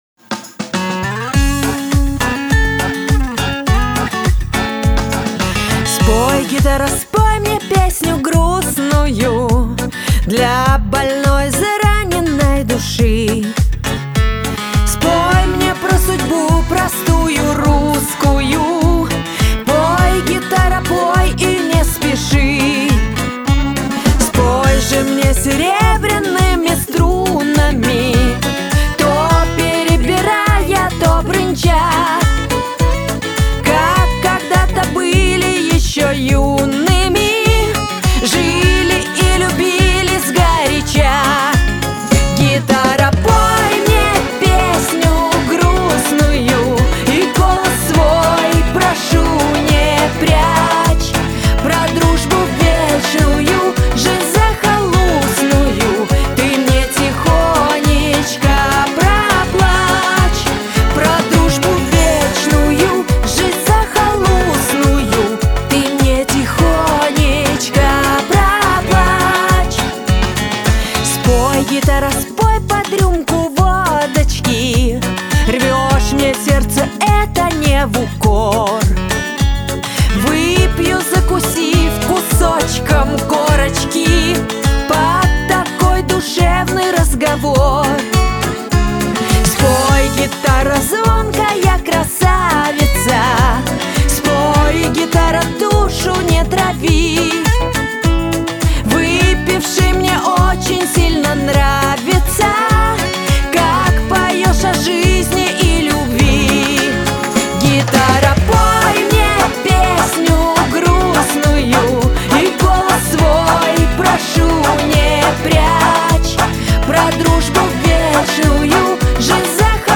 Лирика